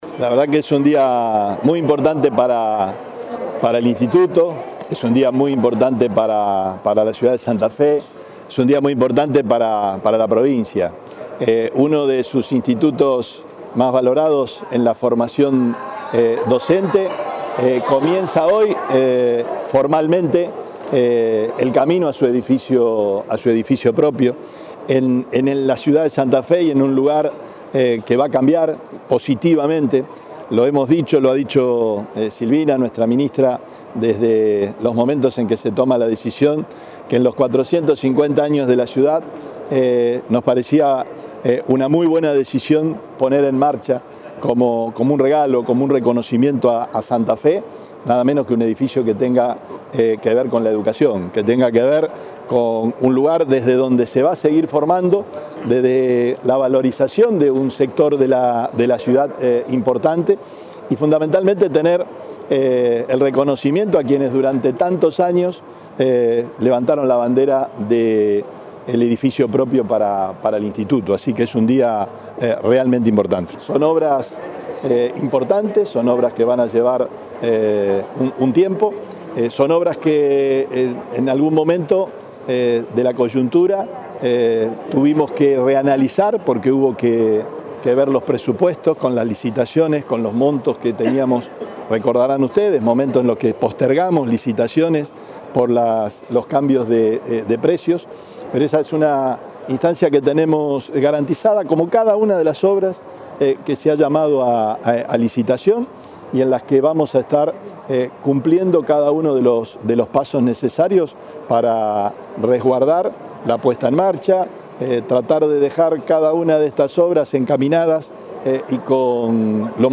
Declaraciones Perotti